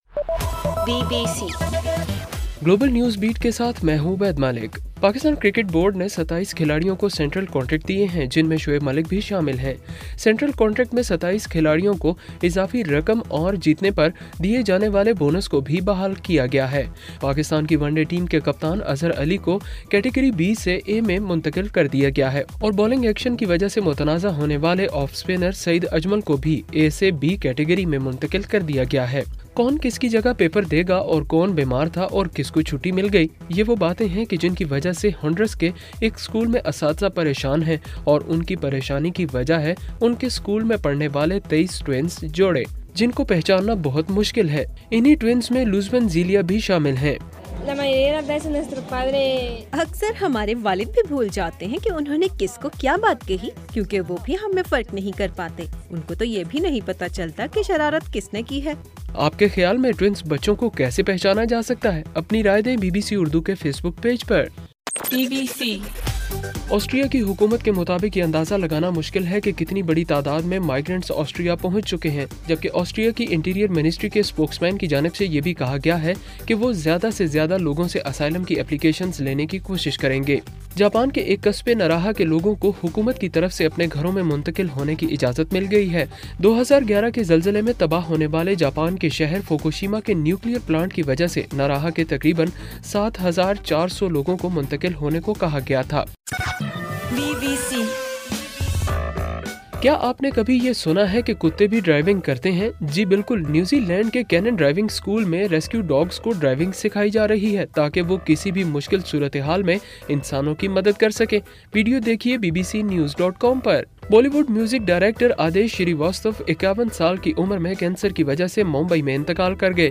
ستمبر 5: رات 9 بجے کا گلوبل نیوز بیٹ بُلیٹن